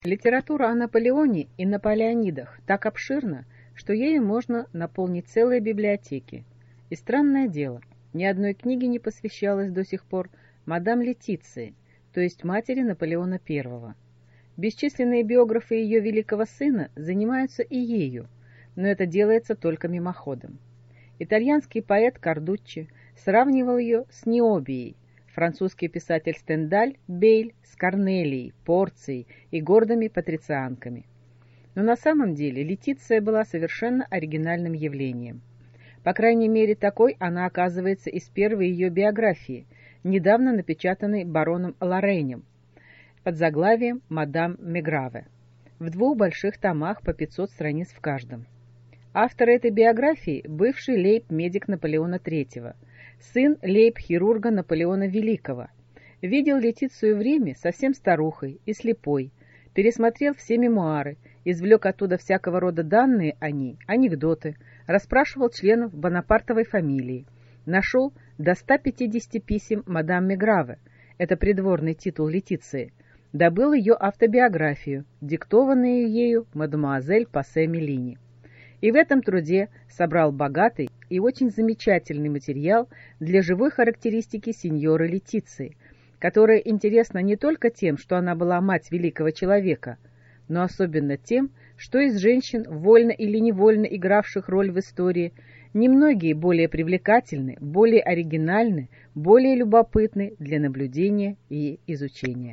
Аудиокнига Мать Наполеона I | Библиотека аудиокниг